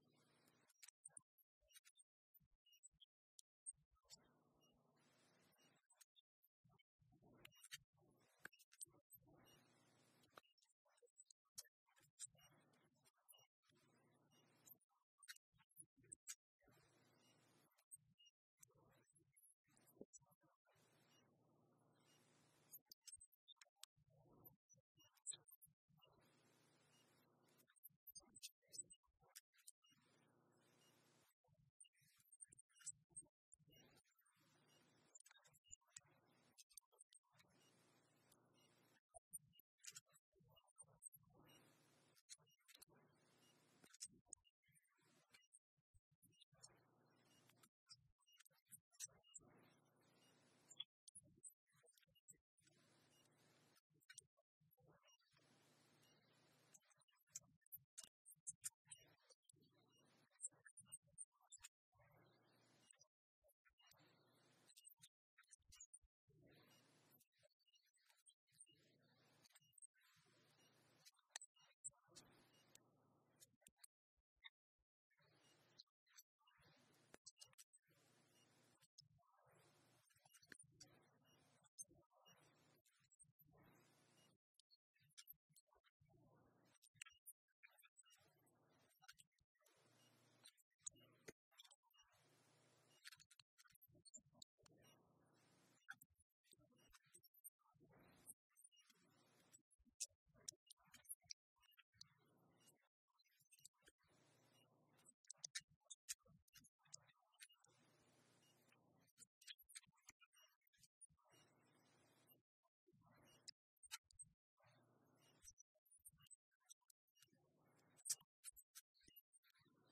This week is our first week back in the church building!
There is a small issue with audio on some devices if they are set to mono mode.